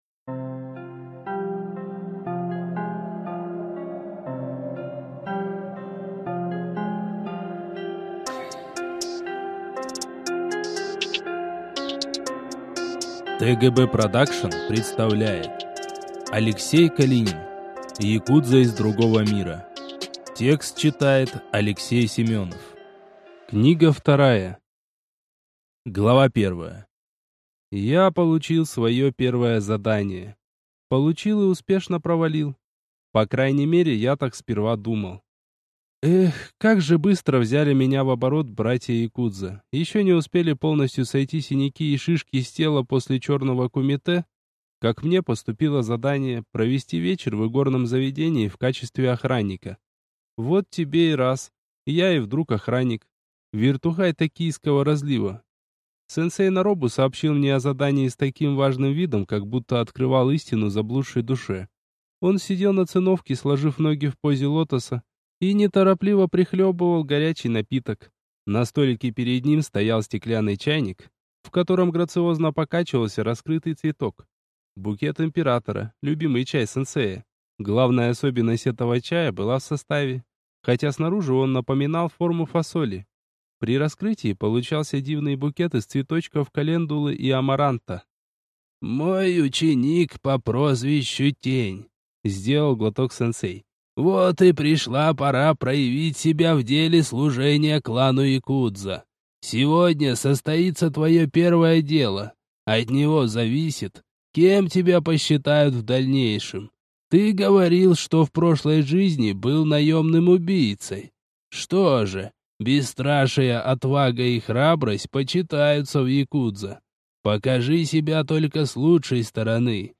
Аудиокнига Якудза из другого мира 2 | Библиотека аудиокниг